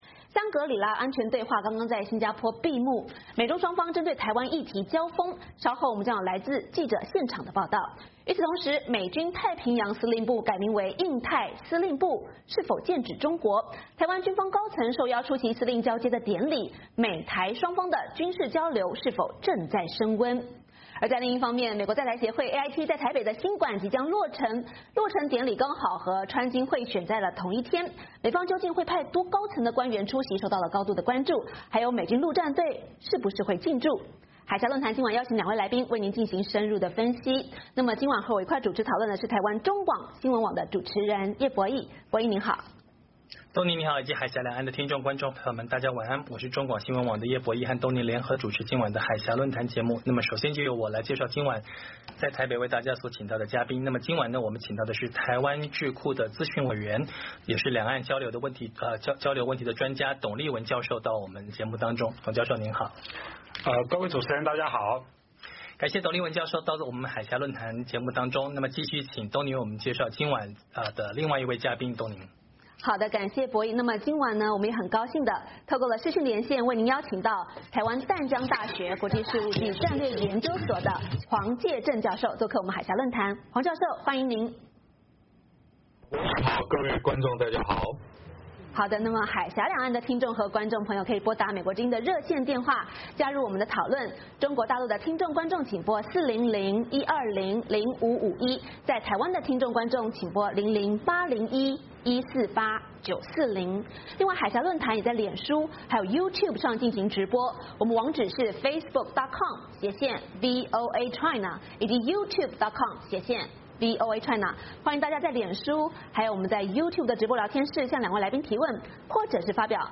节目中有来自现场的第一手报导。与此同时，美国国防部长针对中国在南中国海的军事化发出警告，美中双方是否在南海＋黄海＋台海＋东海这四海展开新一轮较量？